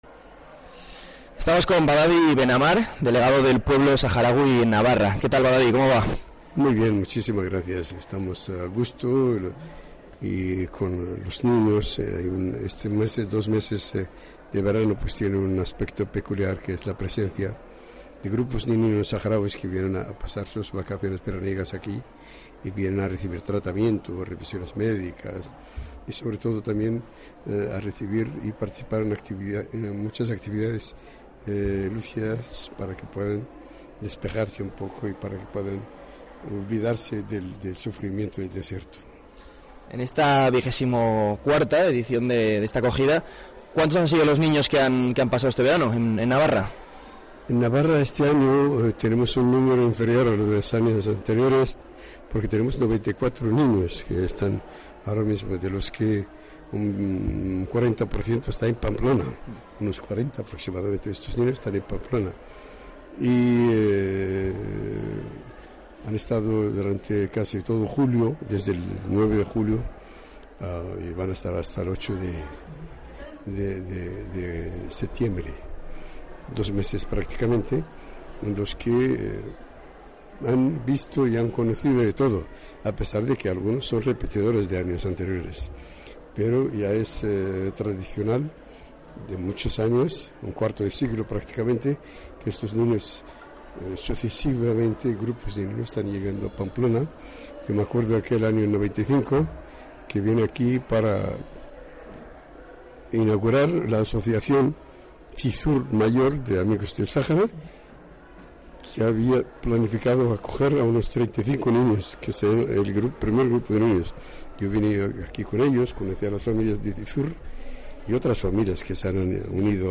COPE NAVARRA Entrevista